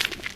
default_gravel_footstep.4.ogg